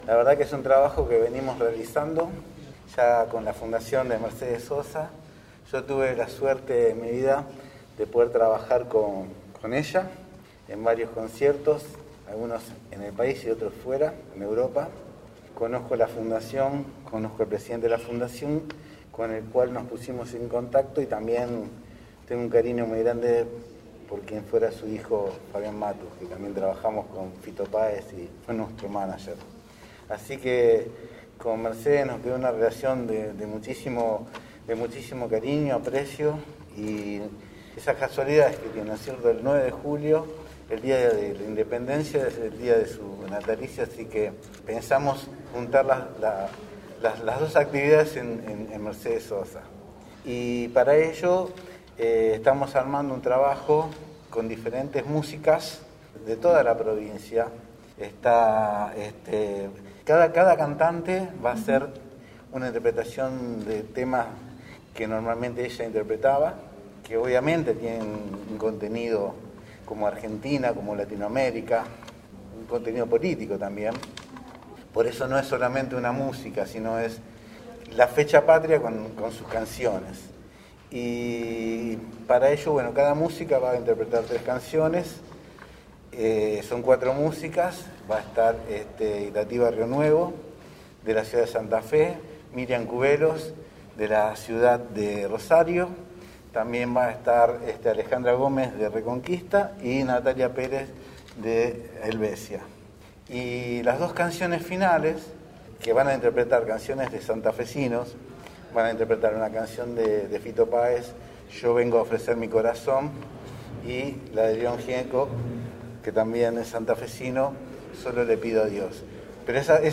Ministro de Cultura